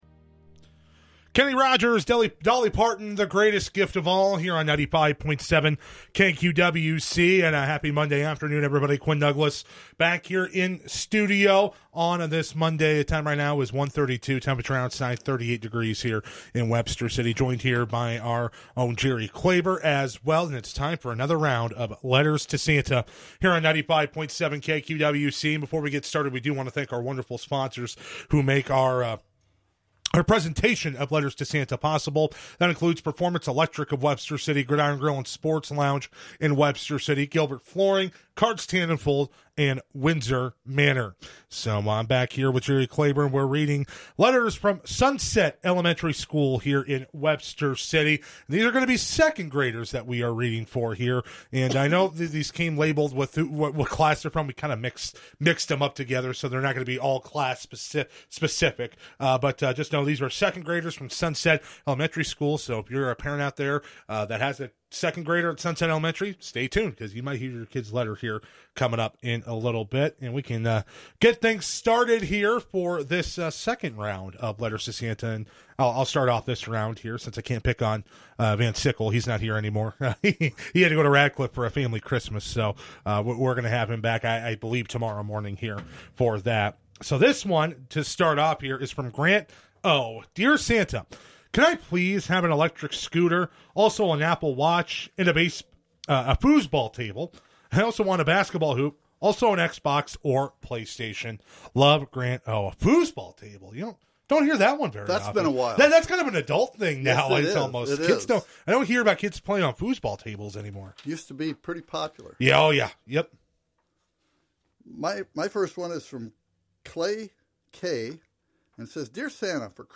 Letters to Santa program, featuring letters from kids at Sunset Elementary School in Webster City!